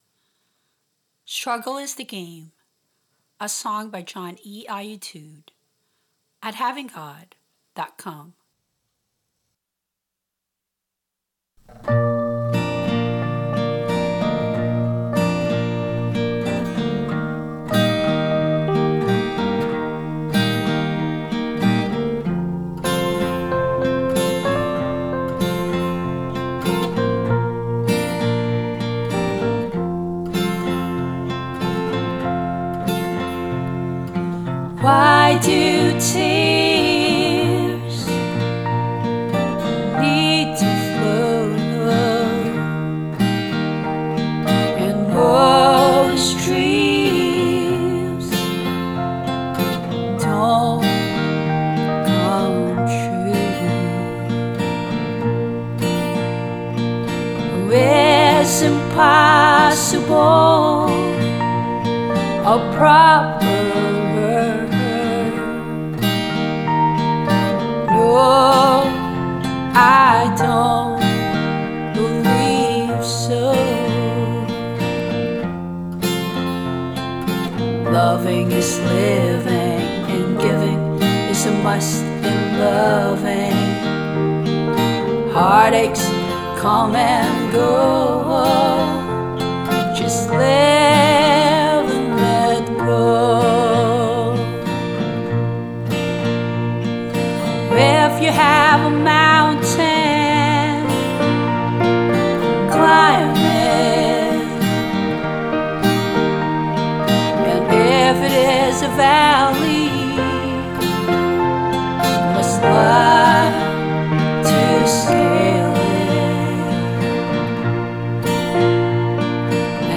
Vocals, guitar, bass and bongos
Keyboard, strings and organ